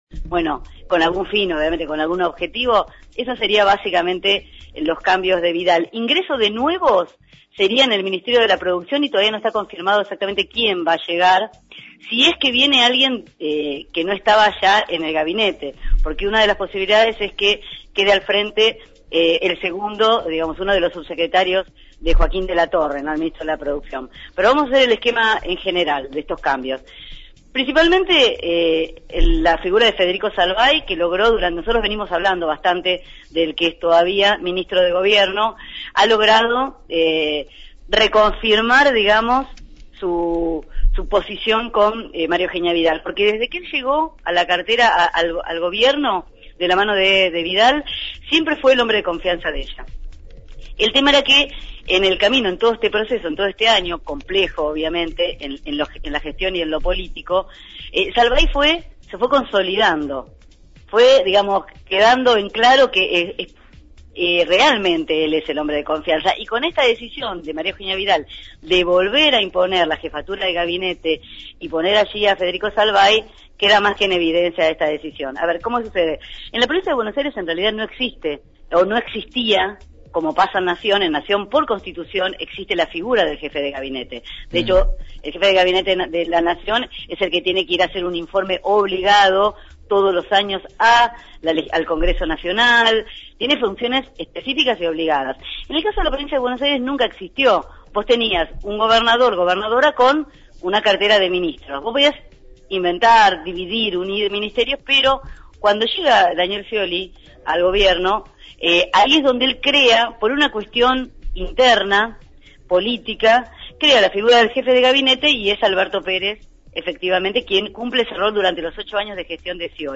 análisis Legislativo